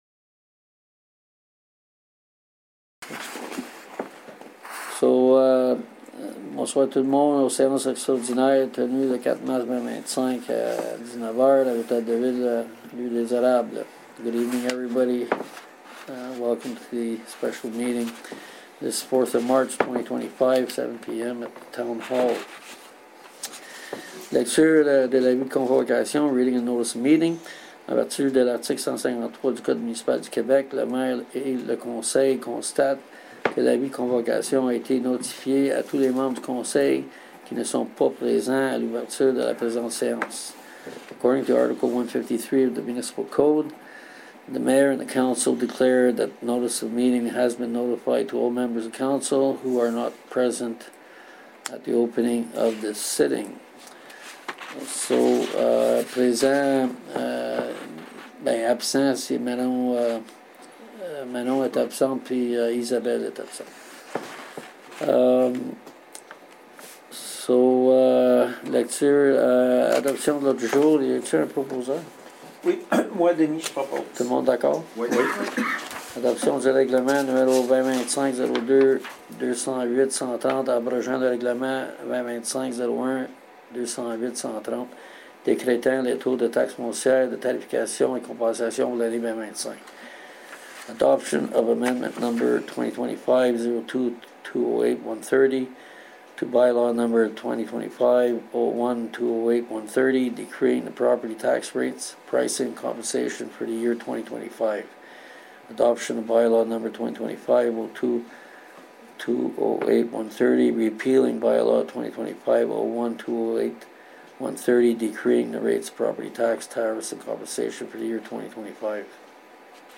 SÉANCE EXTRAORDINAIRE DU 4 MARS 2025/SPECIAL SESSION OF MARCH 11, 2025